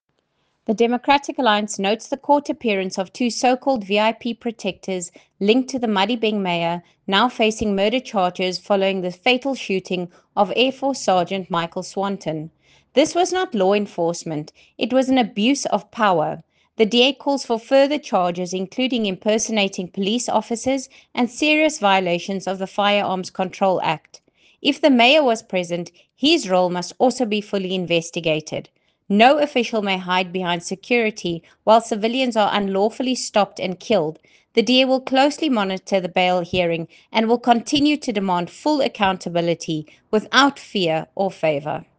Issued by Lisa Schickerling MP – DA Spokesperson on Police
English and Afrikaans soundbite by Lisa Schickerling MP